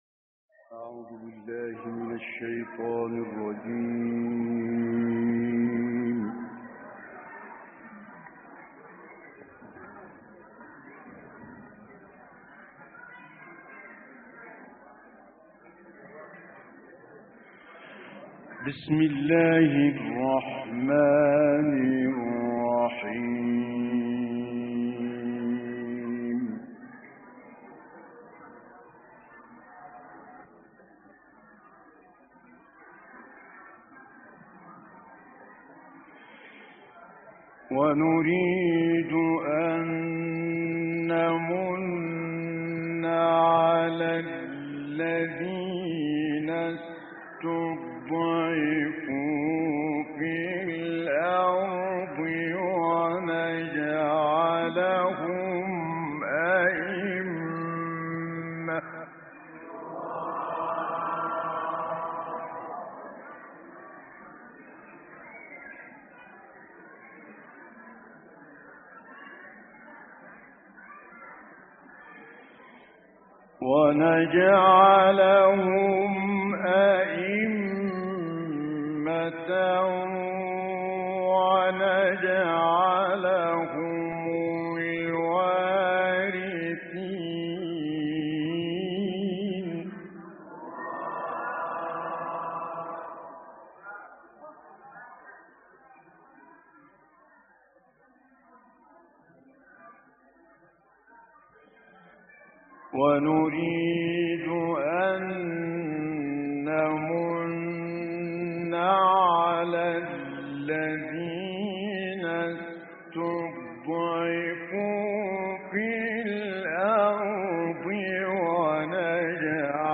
دانلود قرائت سوره های قصص 5 تا 14 و نازعات 27 تا آخر - استاد متولی عبدالعال